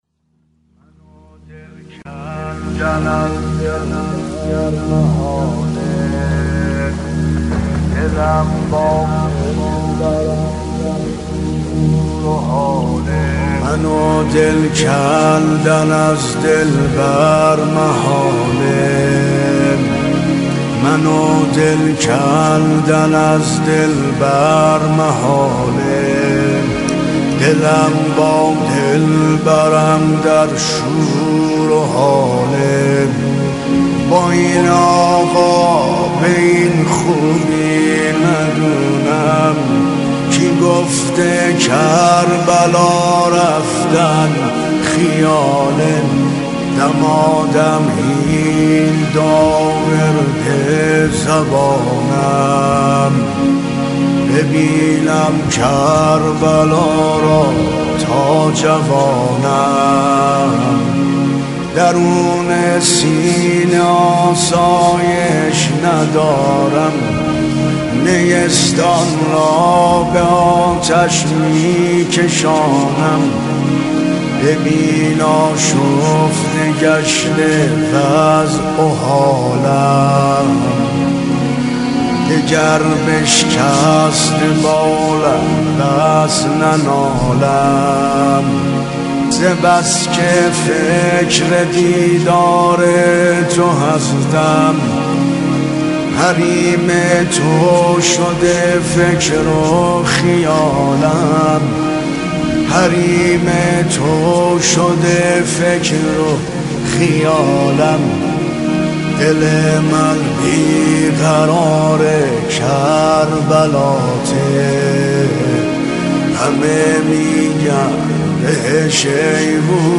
مداحی های پیاده روی اربعین